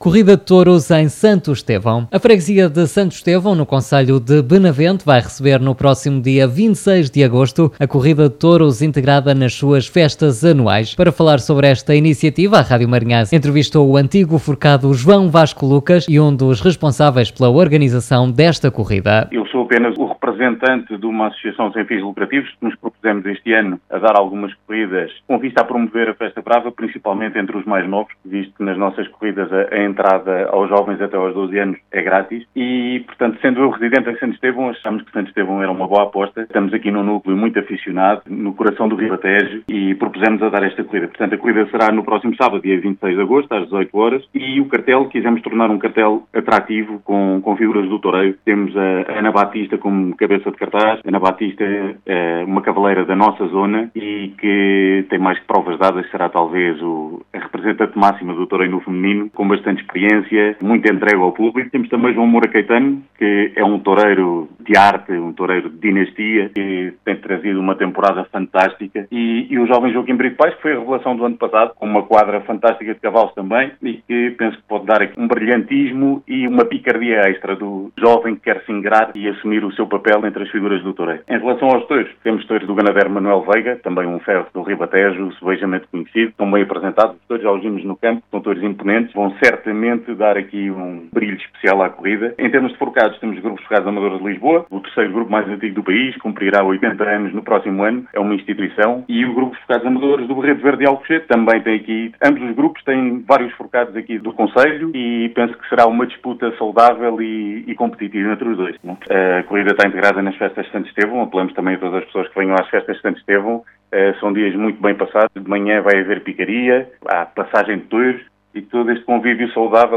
Para saber mais sobre esta Corrida, a Rádio Marinhais entrevistou